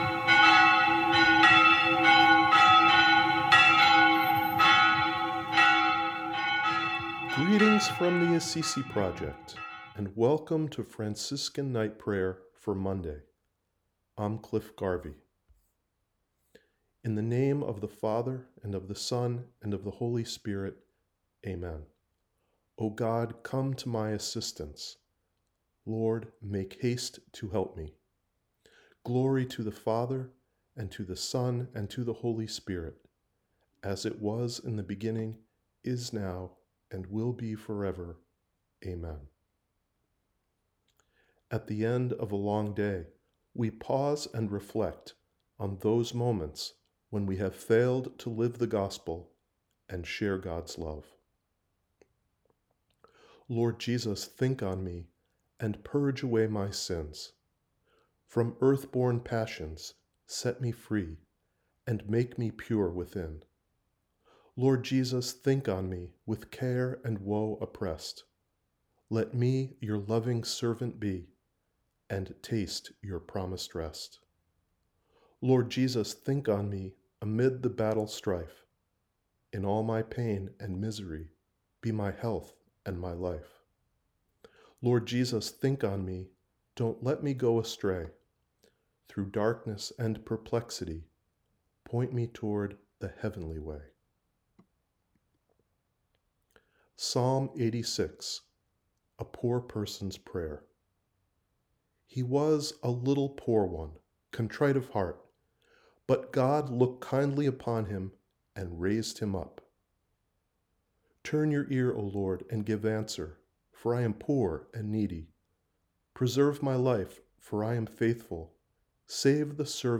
ap-mon-night-prayer.wav